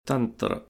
Hindu vocabulary pronunciation